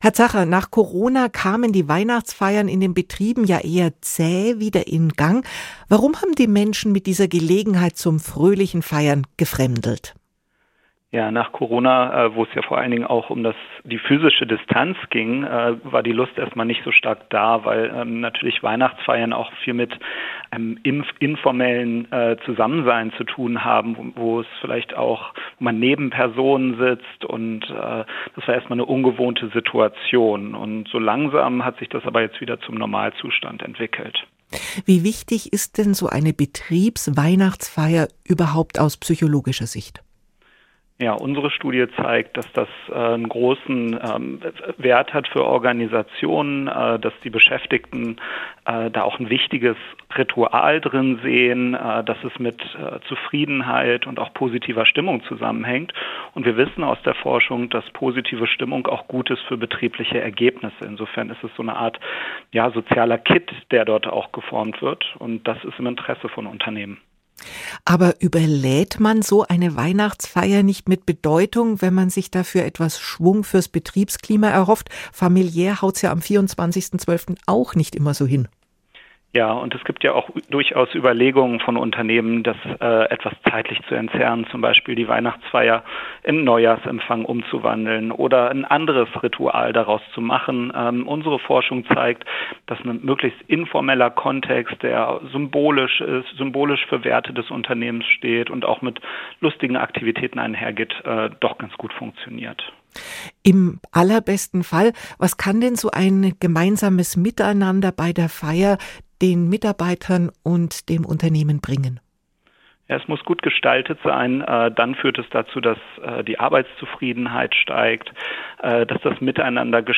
Ein Arbeitspsychologe gibt Tipps im Interview.